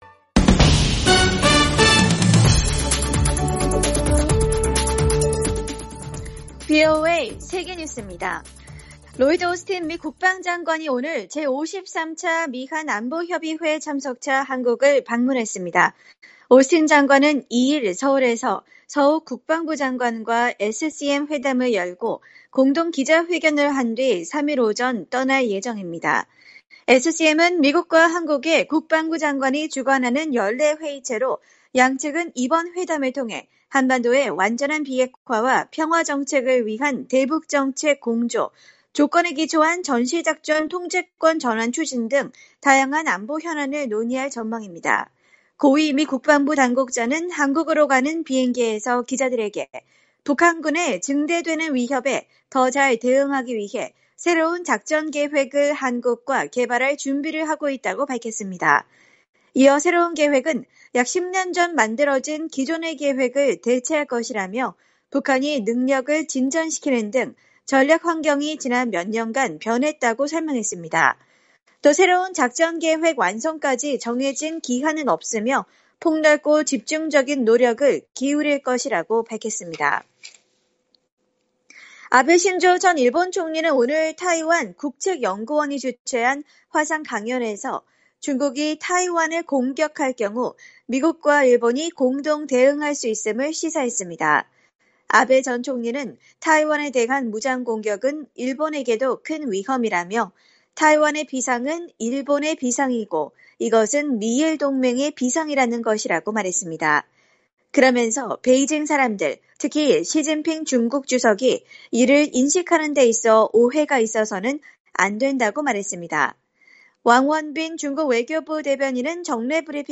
세계 뉴스와 함께 미국의 모든 것을 소개하는 '생방송 여기는 워싱턴입니다', 2021년 12월 1일 저녁 방송입니다. '지구촌 오늘'에서는 남아공이 신종 코로나 변이 '오미크론' 감염 사례를 보고하기 전에, 이미 서유럽에서도 감염 사례가 있었던 것으로 확인된 소식, '아메리카 나우'에서는 재닛 옐런 재무장관과 제롬 파월 연준 의장이 상원 청문회에 출석해 미국 경제가 현재 여러 불확실성에 직면해 있다고 밝힌 소식 전해드립니다.